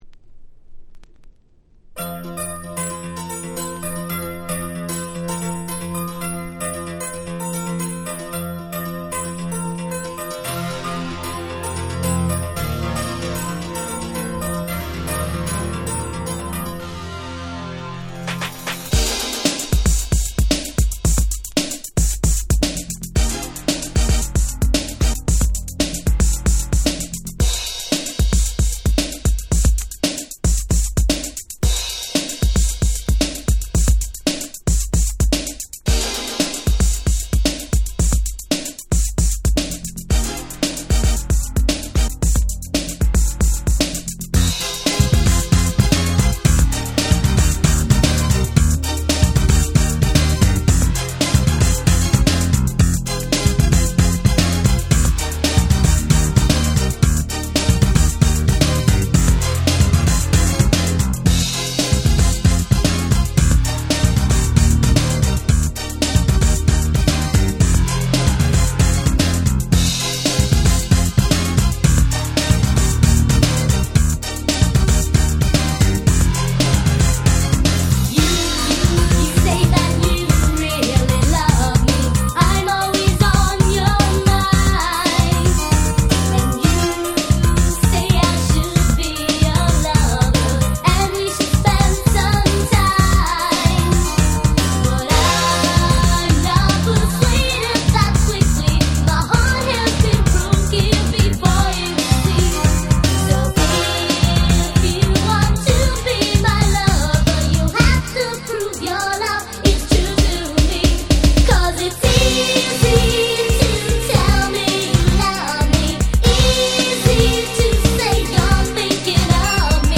86' Super Hit Disco / R&B !!
ラテン風味のユーロビートと言いますか何と言いますか、、とにかくめちゃくちゃ流行りました！！